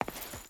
Stone Chain Walk 3.wav